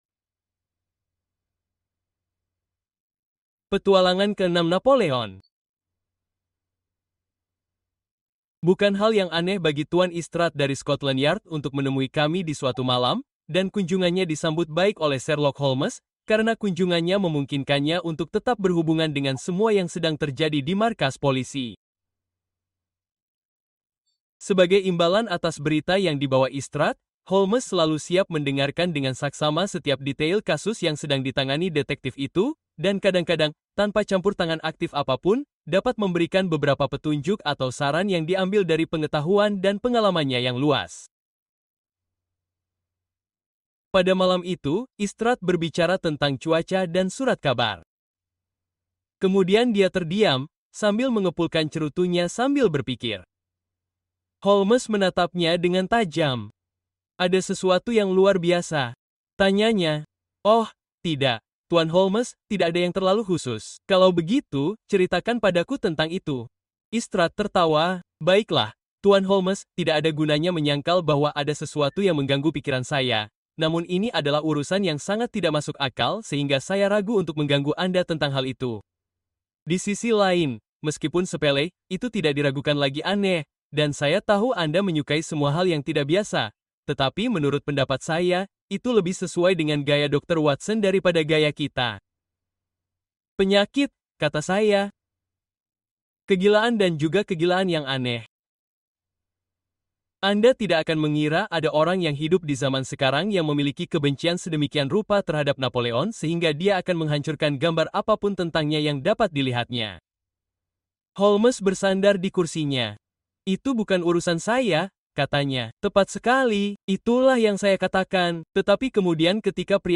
The Beetle-Hunter: Conan Doyle’s Forgotten Mystery (Audiobook)